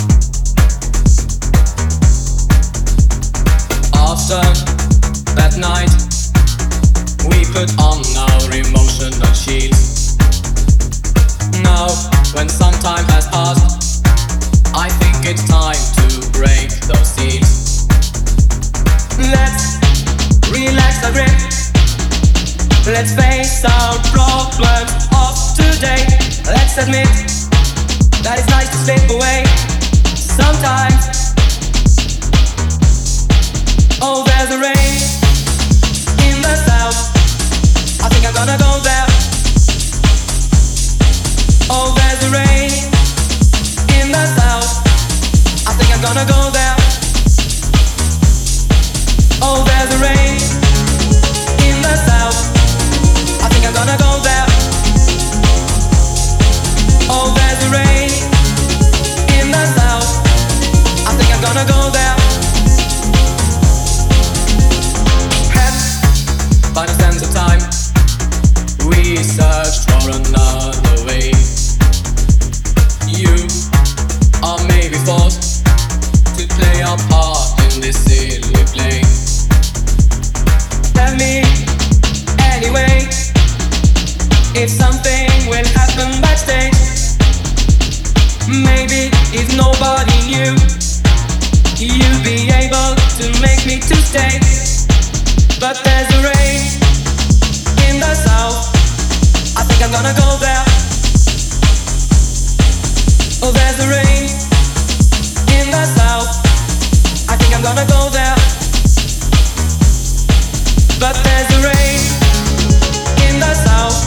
minimal wave